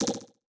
Message Bulletin Echo.wav